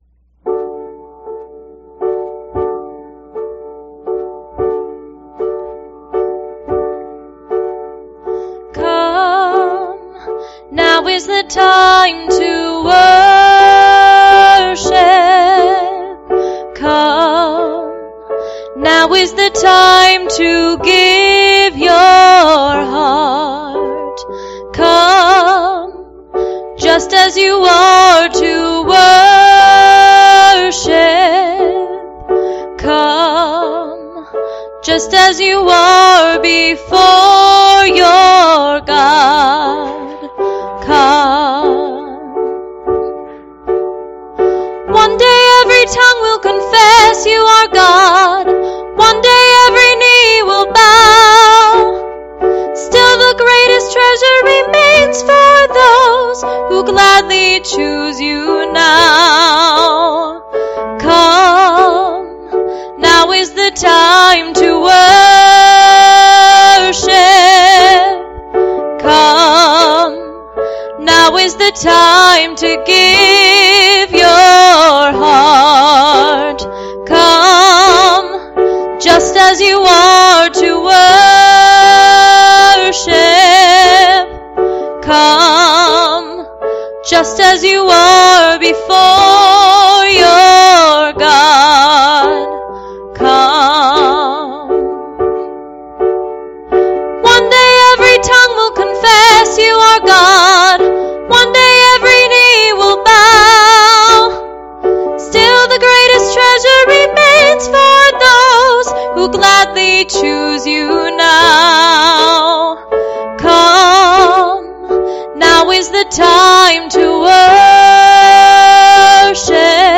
Please, click the arrow below to hear this week's service.